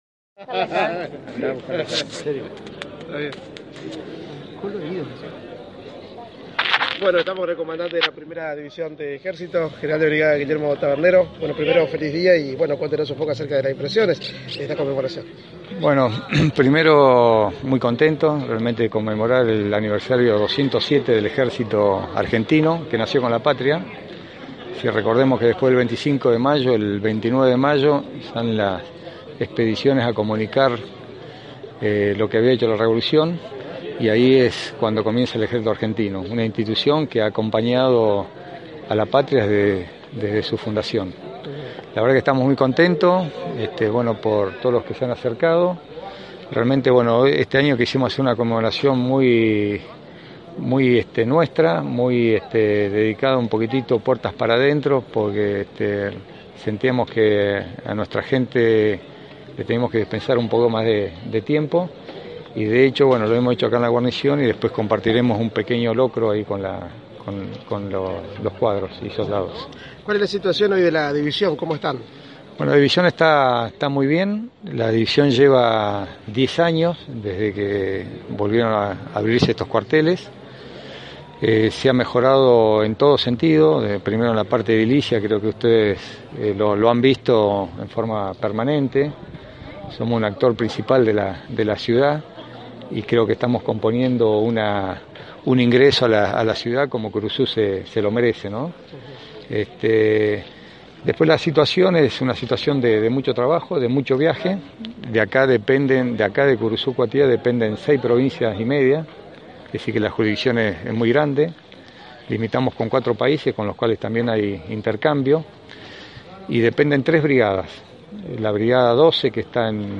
(Audio) El General del Ejército Guillermo Tabernero tras finalizar el acto protocolar en la Plaza de Armas realizó declaraciones a los medios de prensa allí presentes, entre los que se encontraba IusNoticias e indicó su beneplácito por el aniversario del Ejército Argentino.